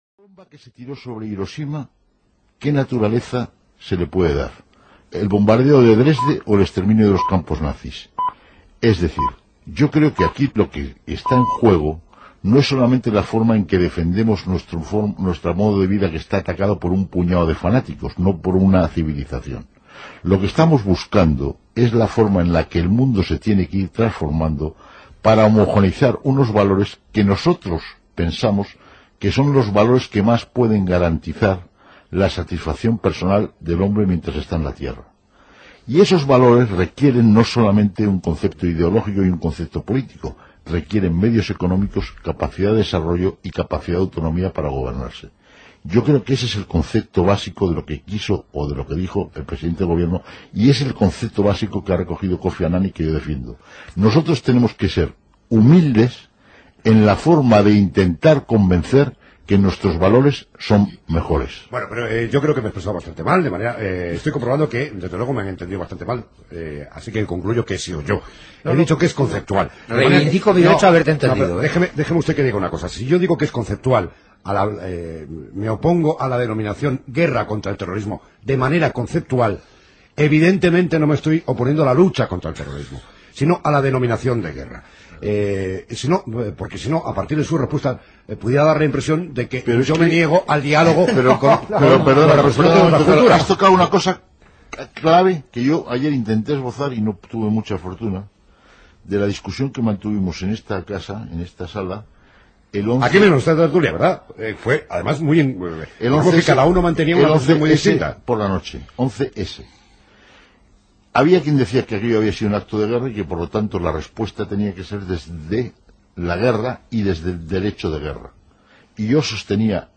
Tertúlia
Gènere radiofònic Informatiu